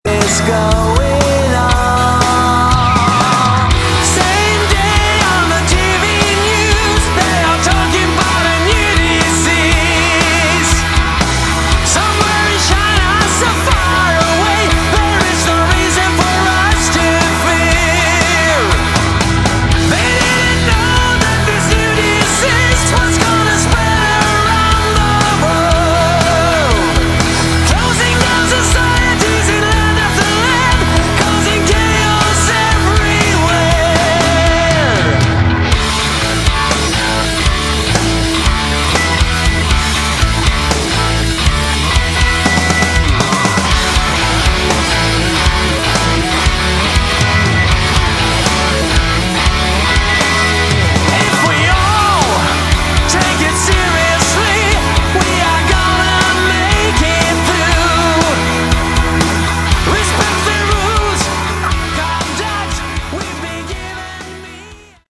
Category: Hard Rock
bass
guitar
vocals
keyboards
drums